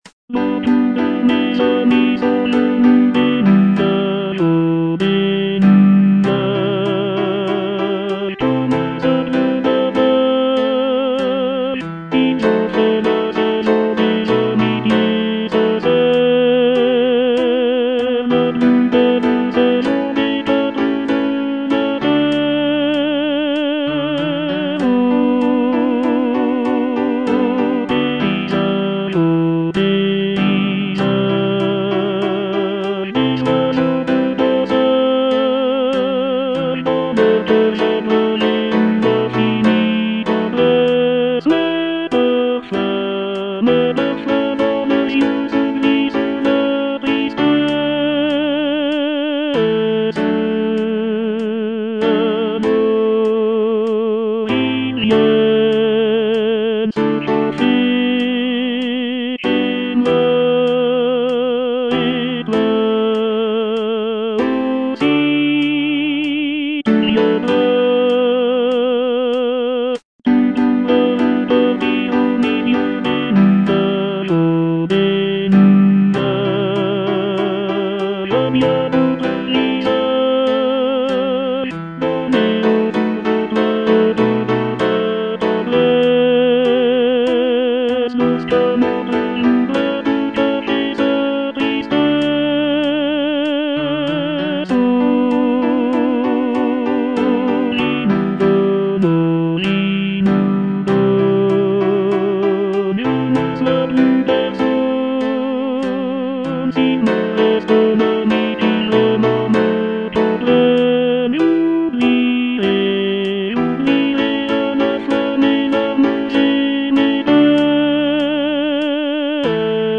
Tenor II (Voice with metronome)
piece for choir